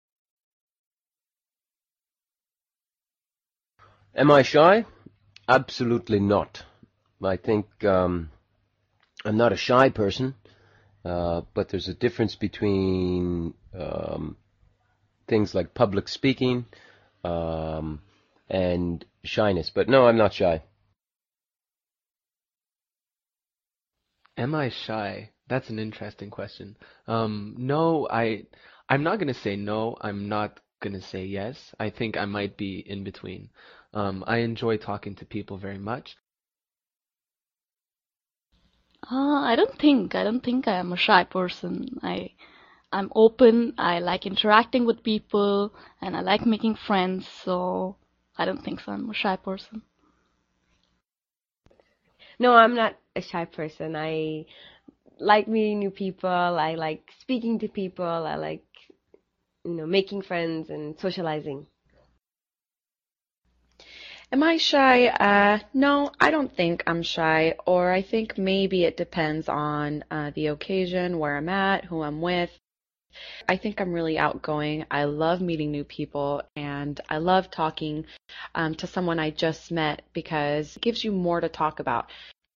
Conversaciones nivel 3.